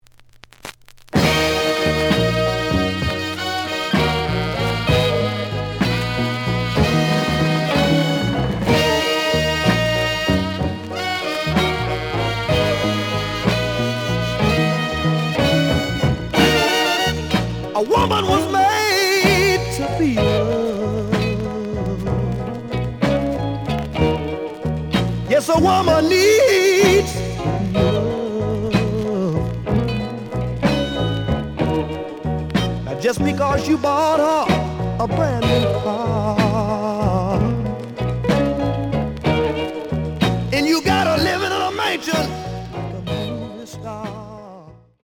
The audio sample is recorded from the actual item.
●Genre: Soul, 60's Soul
Some noise on beginning of B side.)